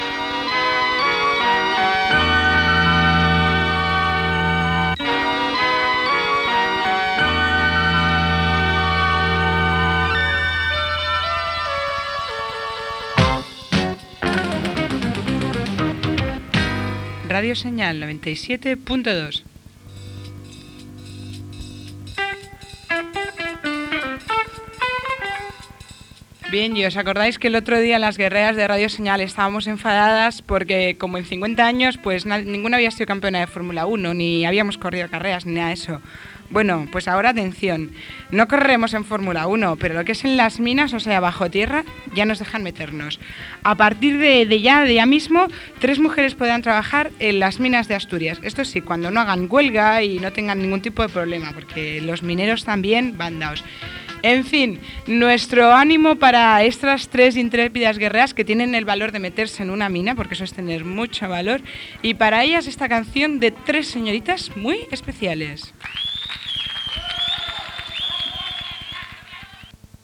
8c605bd3302afe954965f23b3898c9860cc90eb0.mp3 Títol Radio Señal Emissora Radio Señal Titularitat Tercer sector Tercer sector Comercial Descripció Sintonia, indicatiu, comentari sobre les primeres tres dones mineres a Astúries i tema musical.